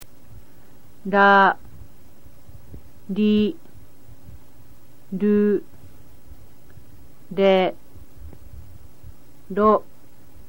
The sound lies somewhere between L and R, but is not either L or R specifically.